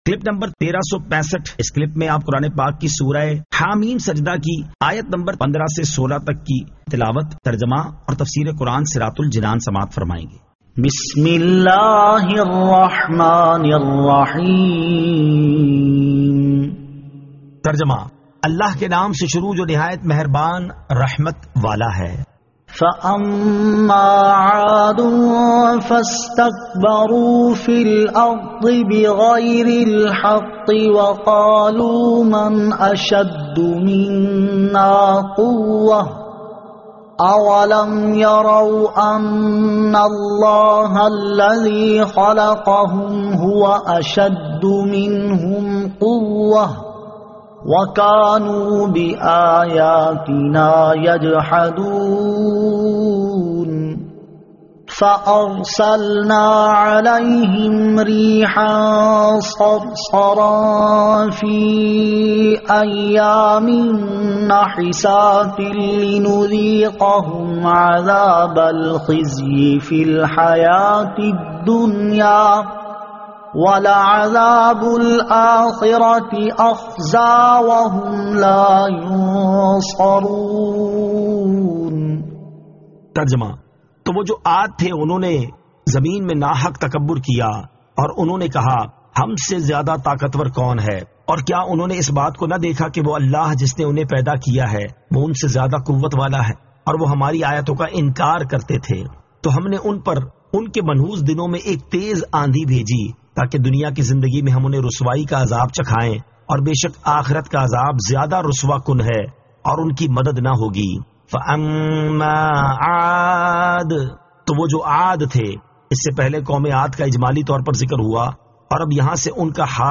Surah Ha-Meem As-Sajdah 15 To 16 Tilawat , Tarjama , Tafseer